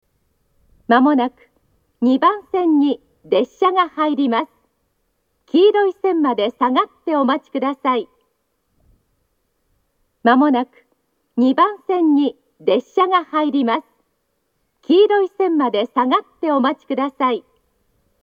発車メロディーと接近放送があります。
２番線接近放送
aizu-wakamatsu-2bannsenn-sekkinn.mp3